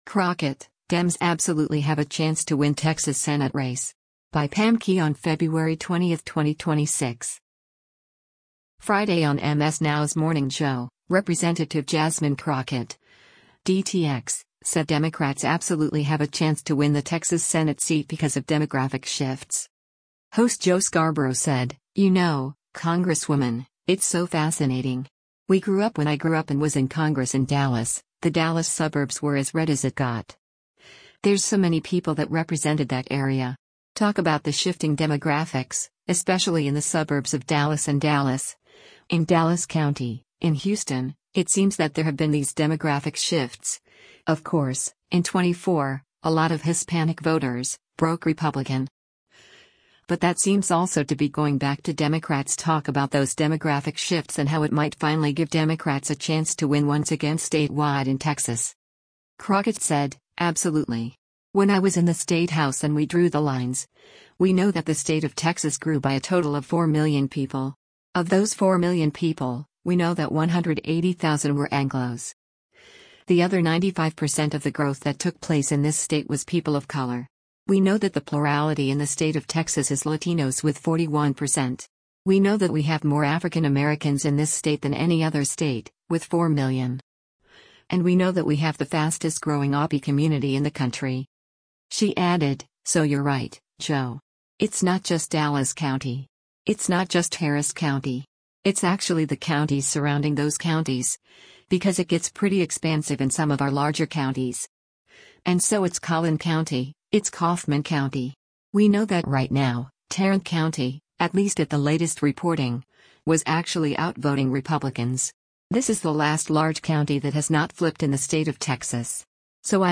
Friday on MS NOW’s “Morning Joe,” Rep. Jasmine Crockett (D-TX) said Democrats “absolutely” have a chance to win the Texas Senate seat because of demographic shifts.